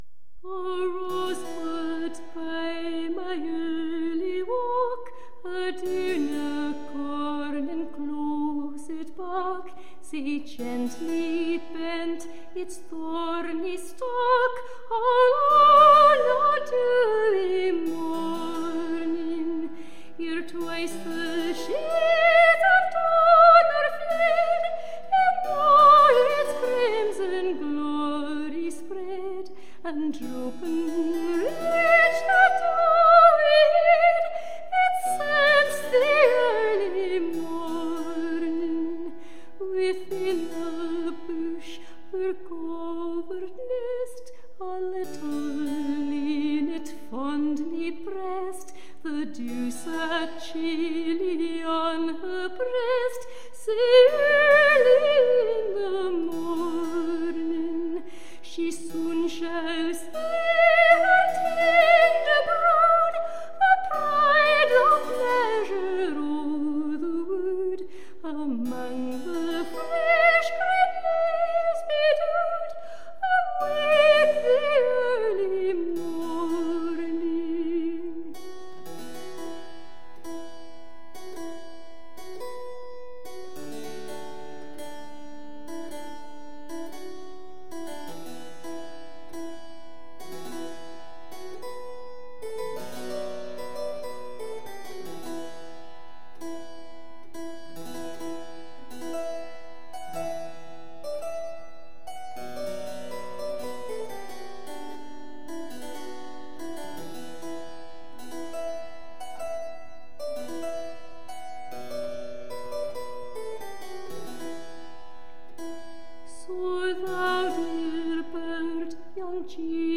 Tagged as: Classical, Folk, Celtic